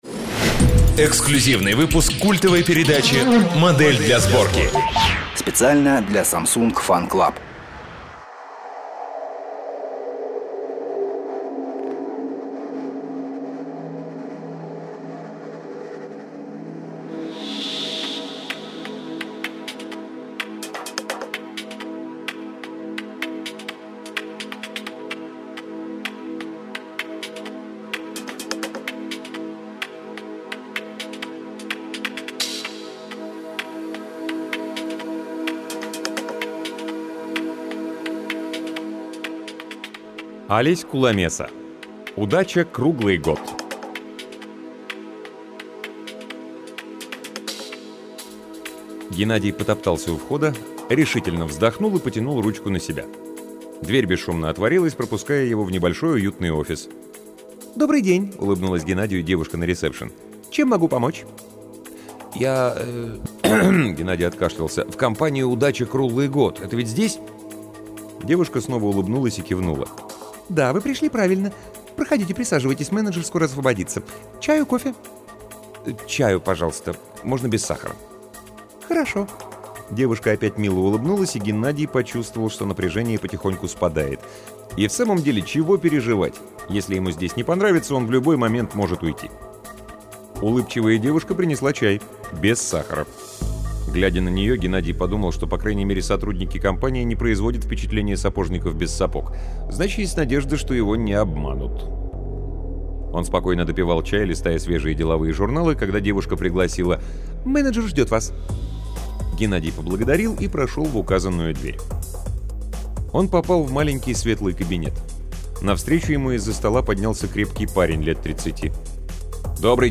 Аудиокнига Алесь Куламеса — Удача круглый год